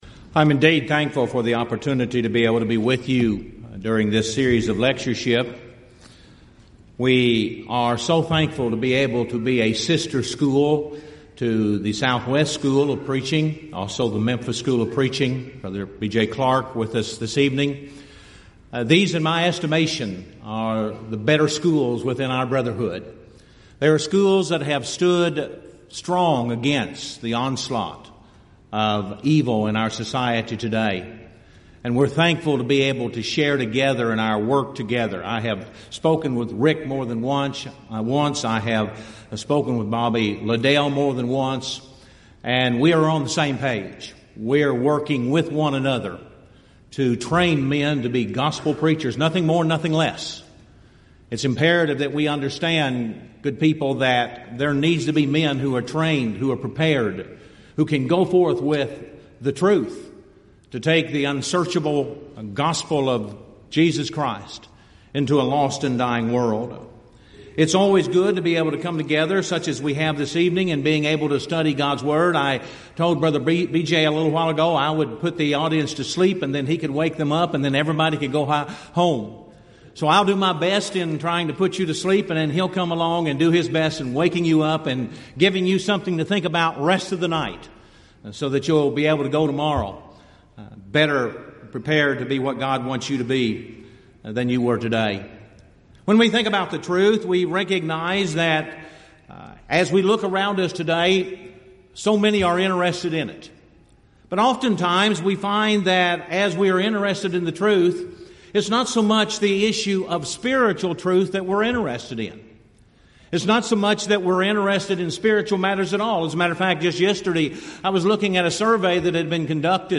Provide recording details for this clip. Event: 30th Annual Southwest Bible Lectures